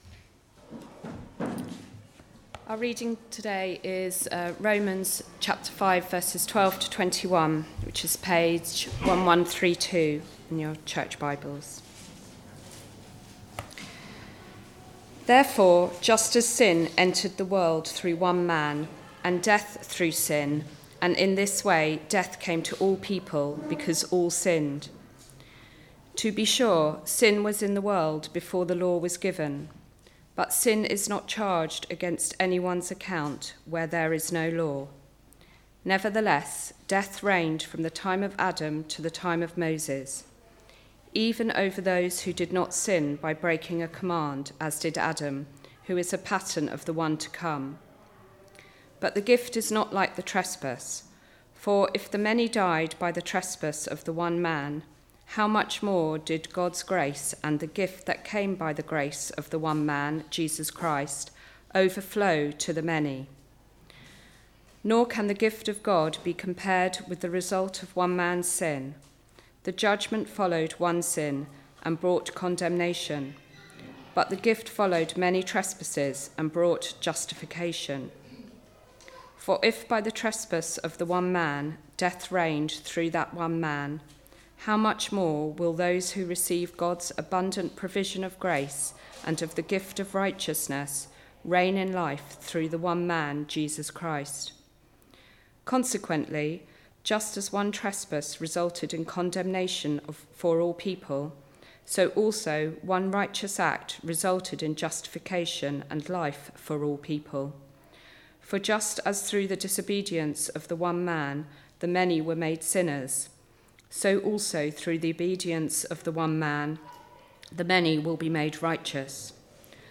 The Riches of Grace Passage: Romans 5:12-21 Service Type: Weekly Service at 4pm « Peace with God